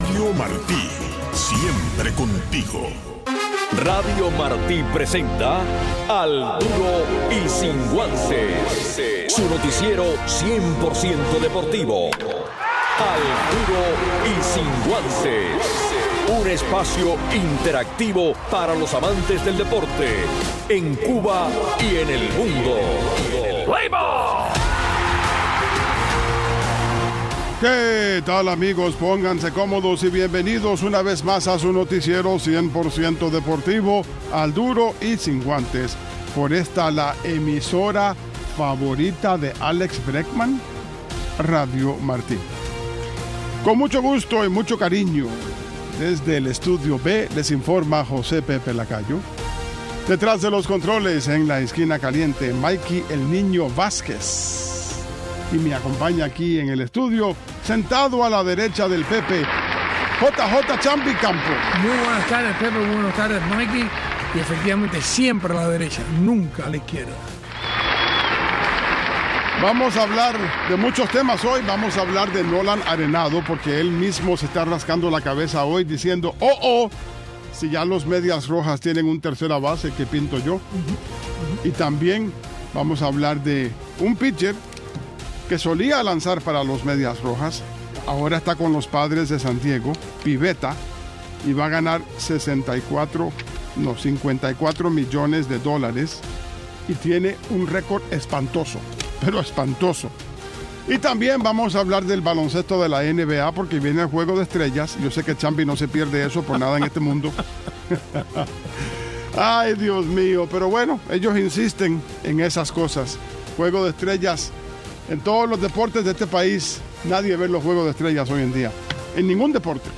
Un resumen deportivo en 60 minutos conducido